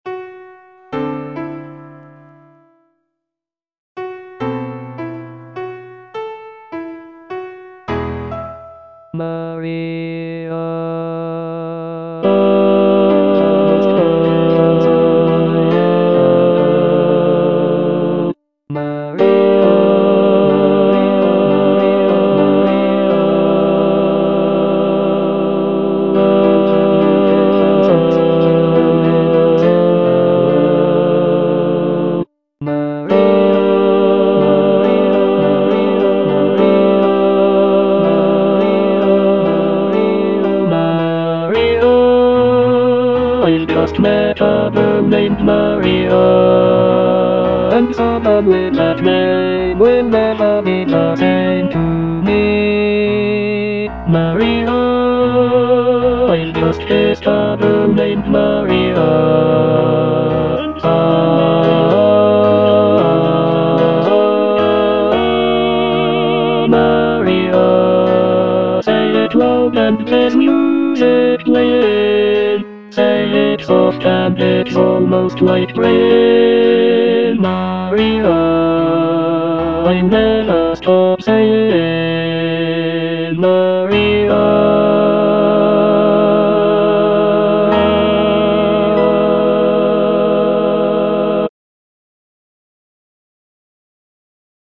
Choral selection
(bass I) (Emphasised voice and other voices) Ads stop